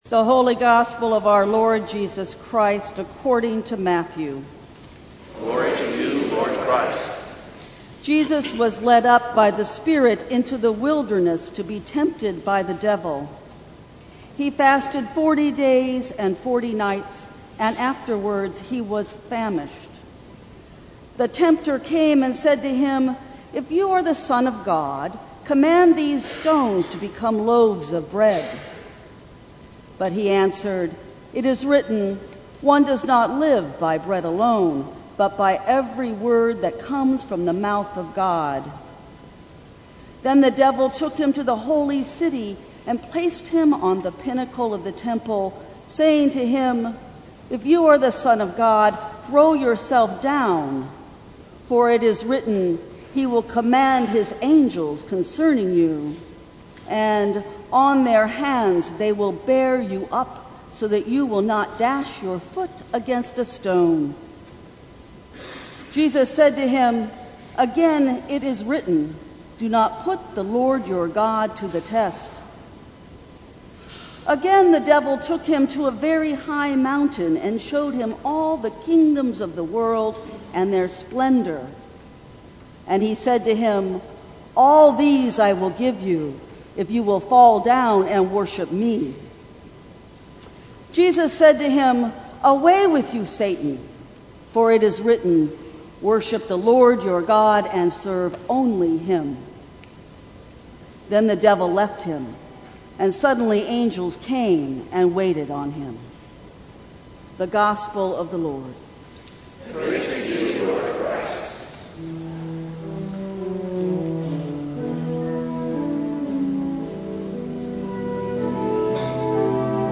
Sermons from St. Cross Episcopal Church First Sunday in Lent Mar 10 2020 | 00:17:15 1x 00:00 / 00:17:15 Subscribe Share Apple Podcasts Spotify Overcast RSS Feed Share Link Embed